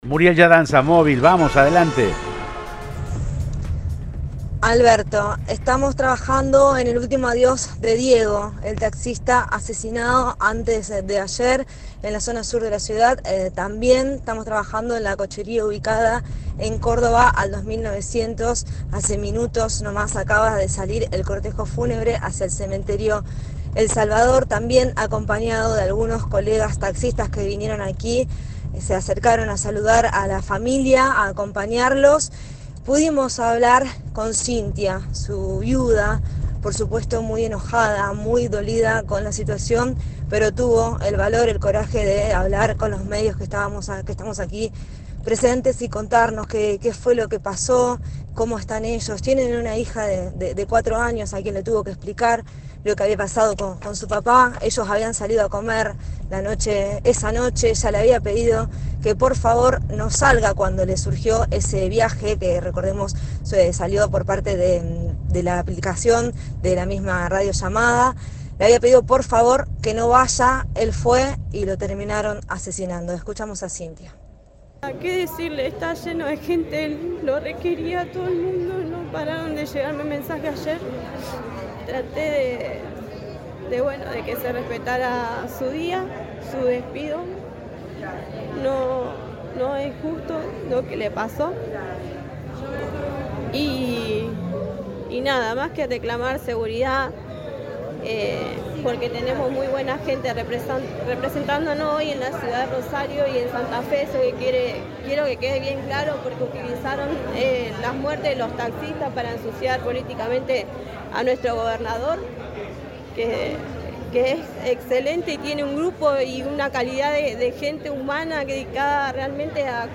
En el cortejo fúnebre, se quejó de la aplicación de radiotaxi, que indicó un viaje a un lugar ubicado en zona roja.